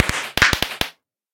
minecraft / sounds / fireworks / twinkle1.ogg
twinkle1.ogg